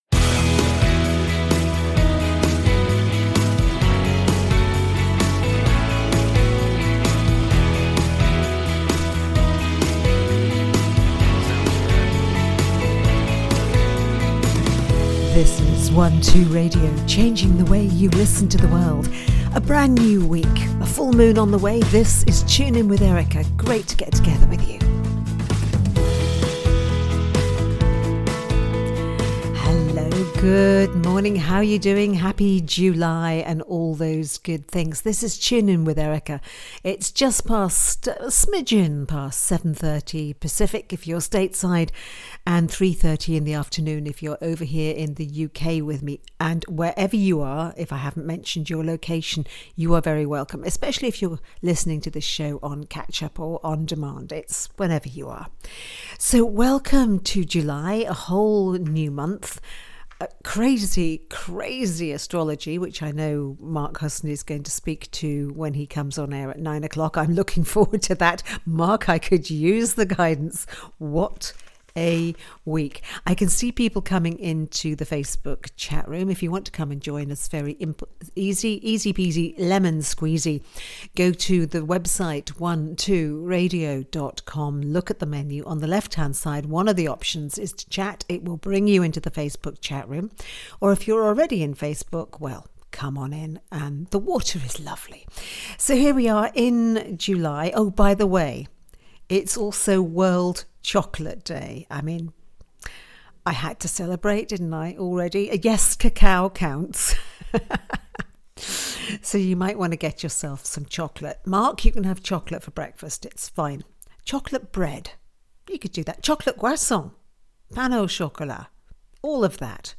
July Starscopes and tuning fork healing.mp3